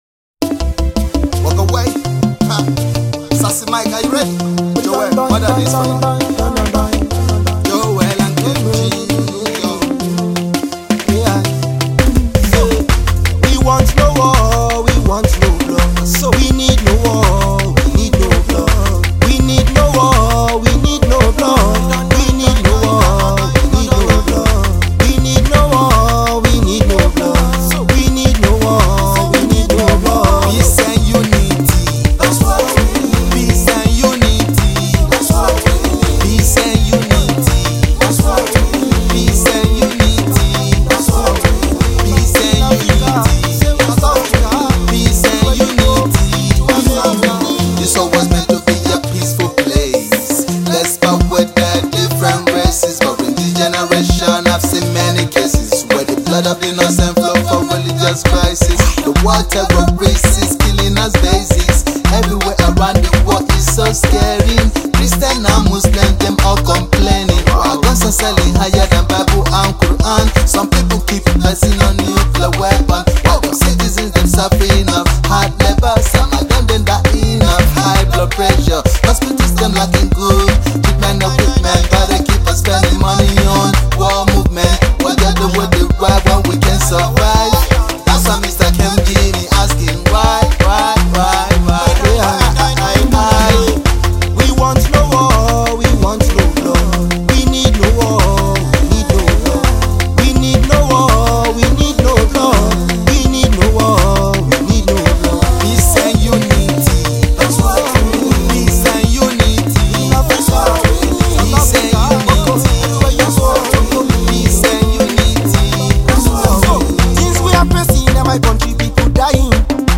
Conscious